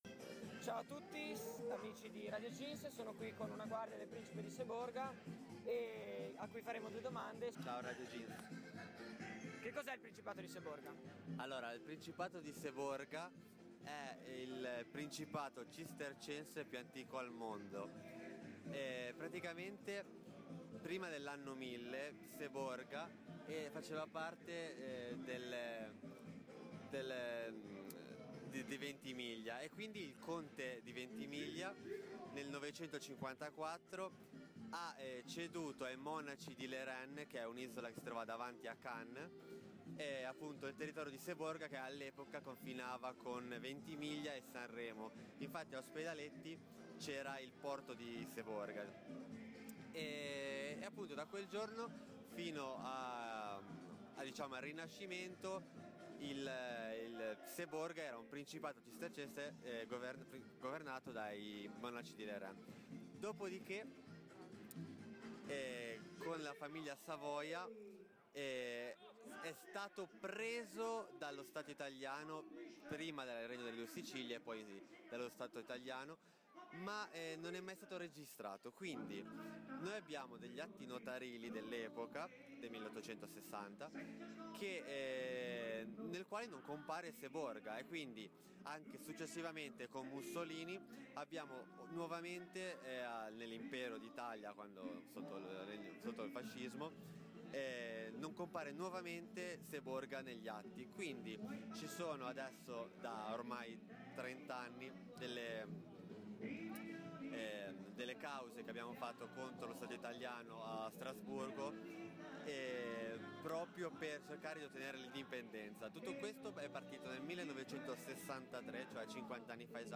Intervista a Guardia del Principe di Seborga